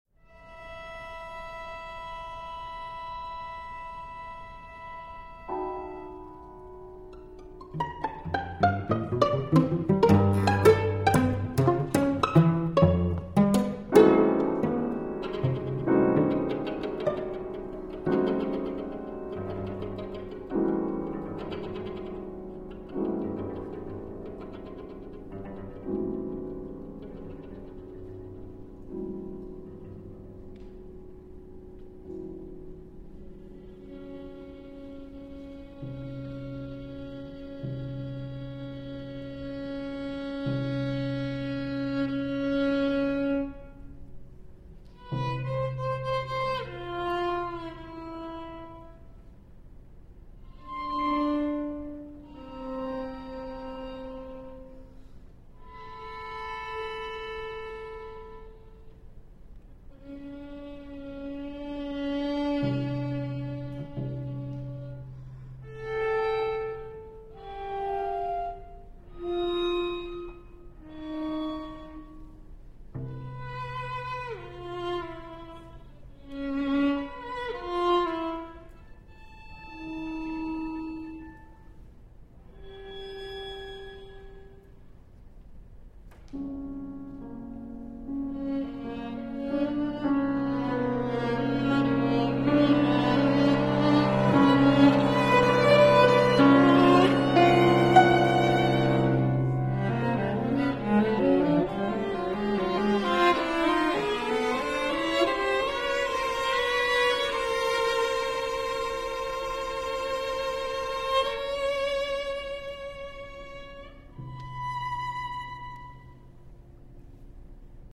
Instrumentation: violin, cello, piano